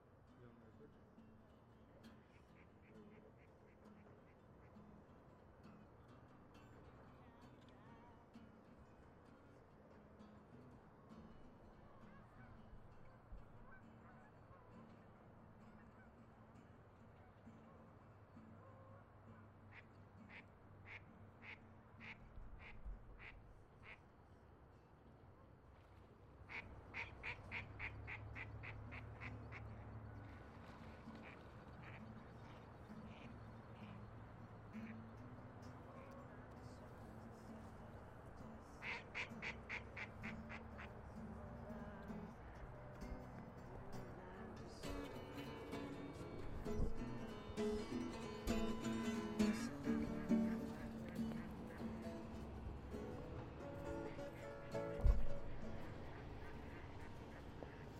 描述：在波尔图的一个巴西聚会的氛围中，人们在交谈和唱歌，同时播放着一些巴西的音乐
Tag: 唱歌 聚会 巴西 音乐 声音 桑巴 葡萄牙